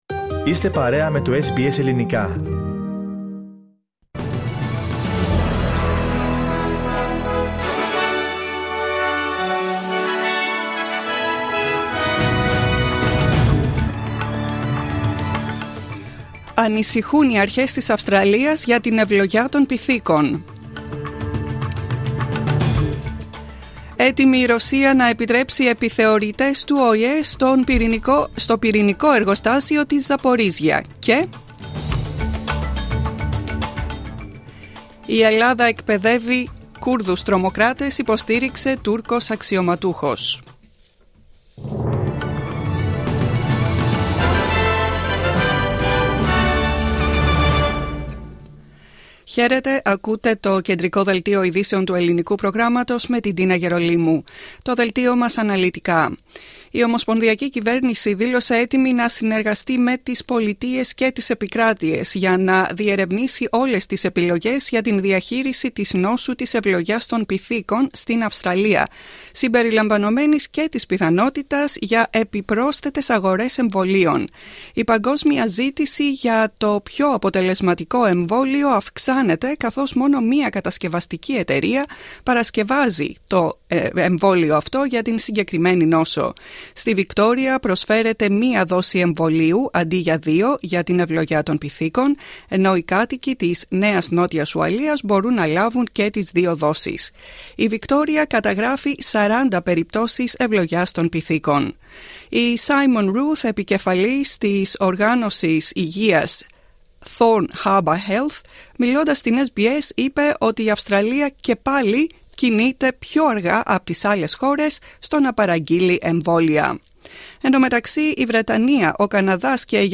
Δελτίο ειδήσεων, 20.8.22